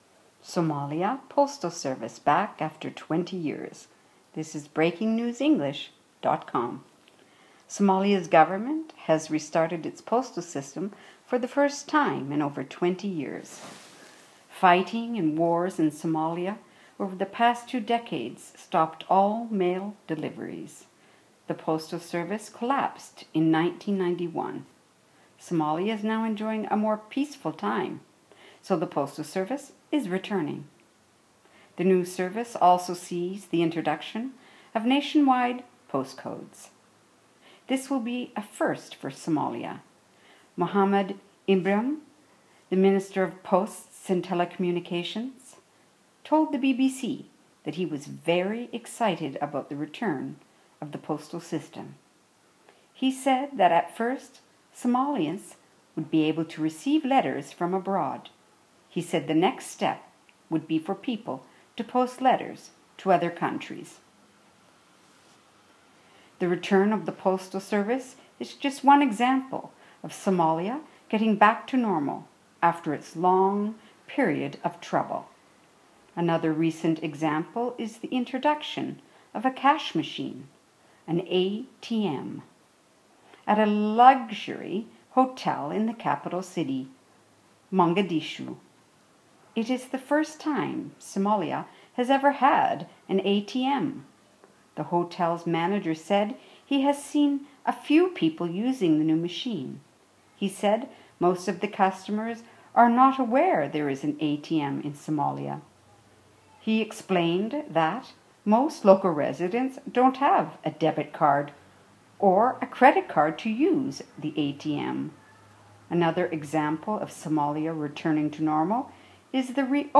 Listen to the story in British English or